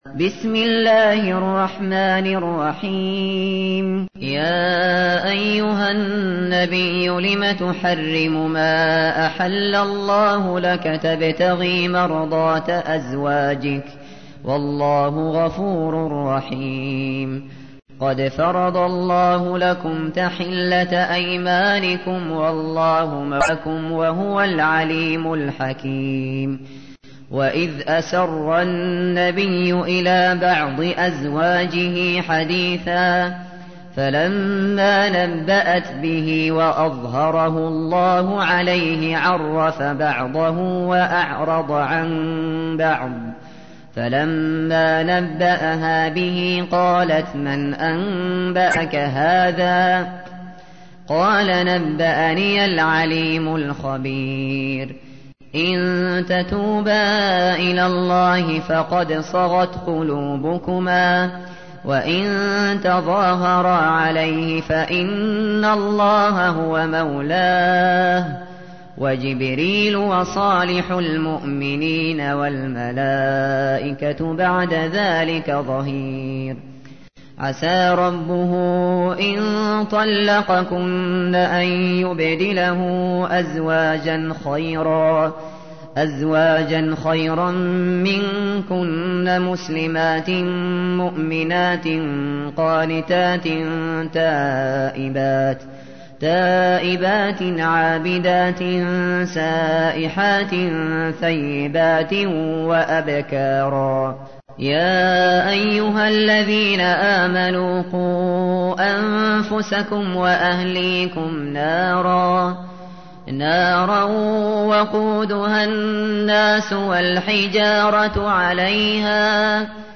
تحميل : 66. سورة التحريم / القارئ الشاطري / القرآن الكريم / موقع يا حسين